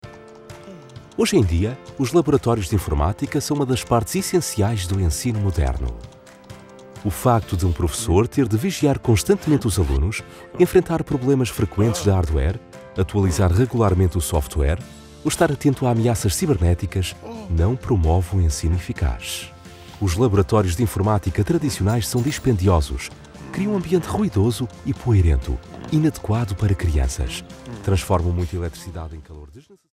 Corporate & Industrial Voice Overs
Portuguese (Iberian)
Adult (30-50)